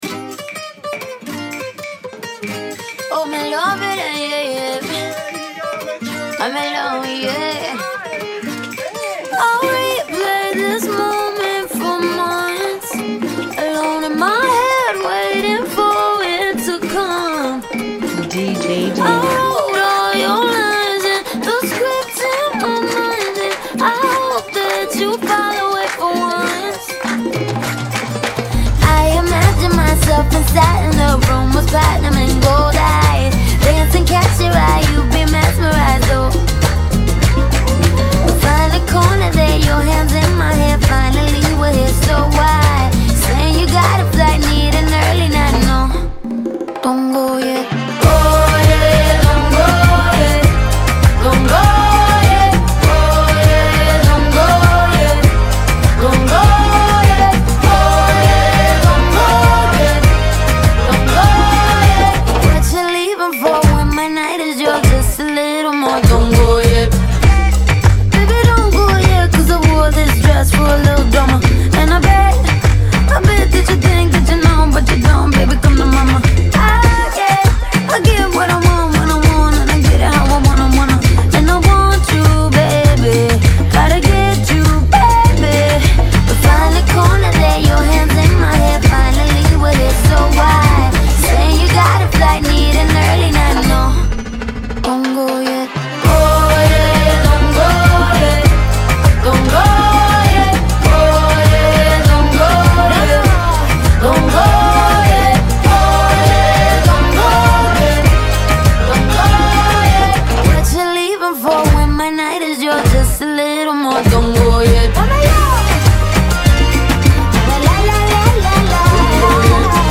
99 BPM
Genre: Salsa Remix